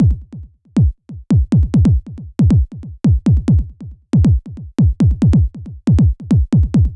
BD ELECTRO-R.wav